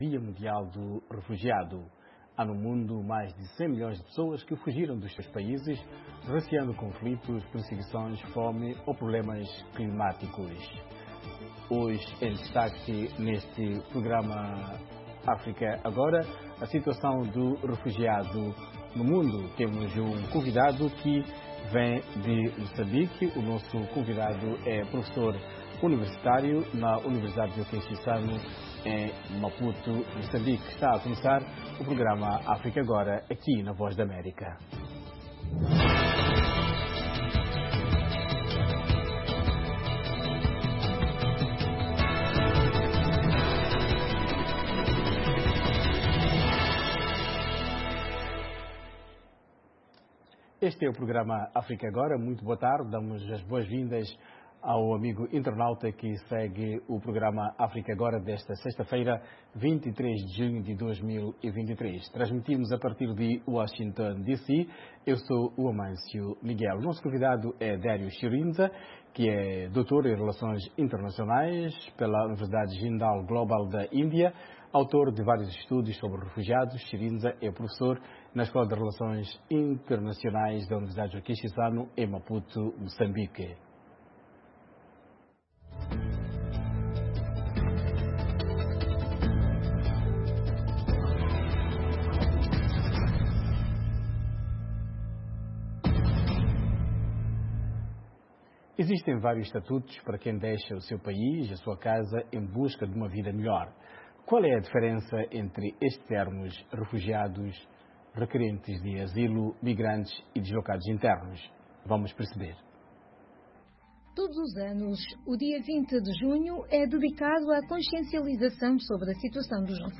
África Agora, o espaço que dá voz às suas preocupaçōes. Especialistas convidados da VOA irão comentar... com a moderação da Voz da América. Um debate sobre temas actuais da África Lusófona.